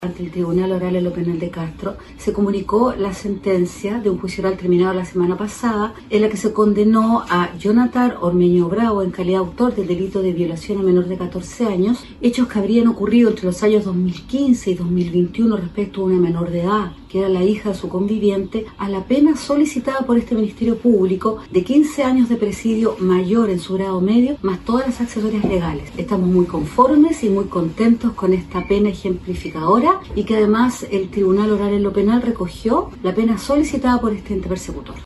La sentencia fue comunicada ante el Tribunal Oral en lo Penal de Castro según lo informó la Fiscal de Quellón Karyn Alegría: